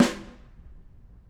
Snare2-HitSN_v3_rr2_Sum.wav